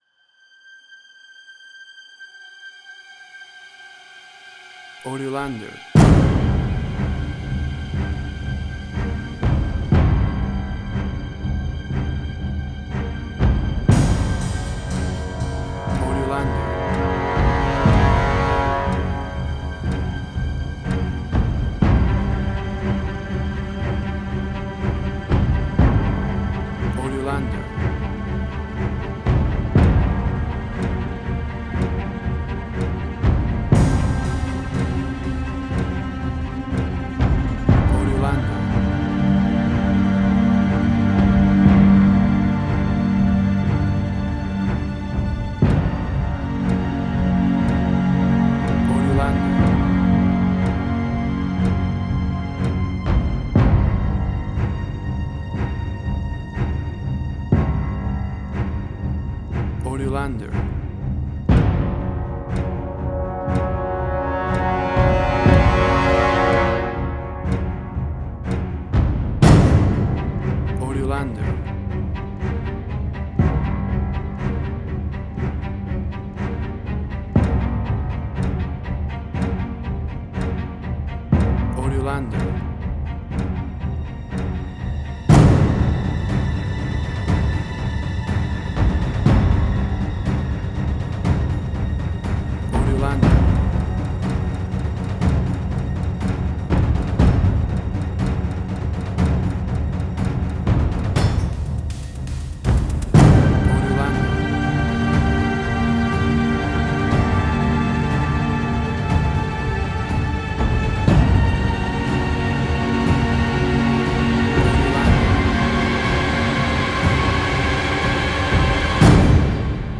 Middle Eastern Fusion.
Tempo (BPM): 60